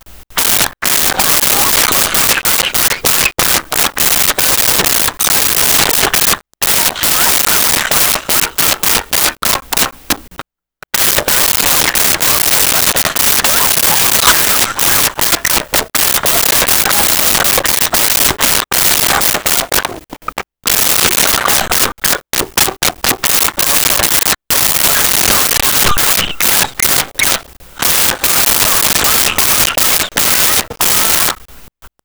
Laughing Female
Laughing Female.wav